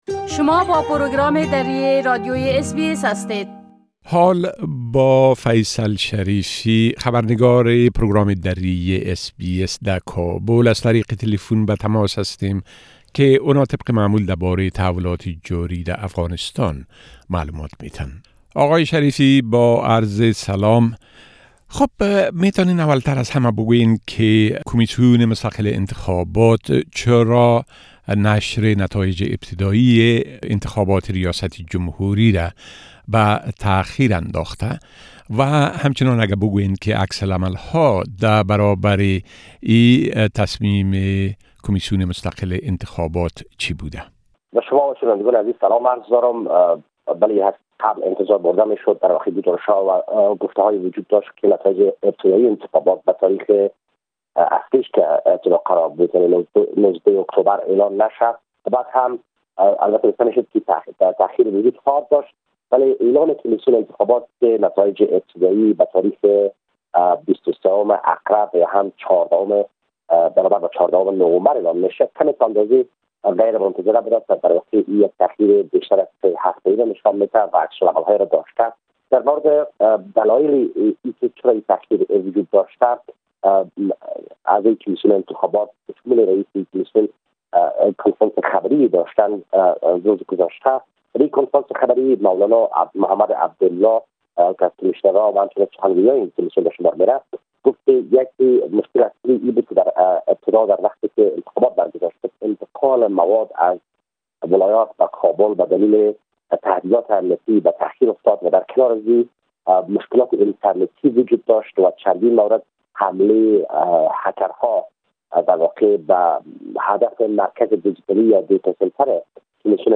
A report from our correspondent in Afghanistan which can be heard here in Dari language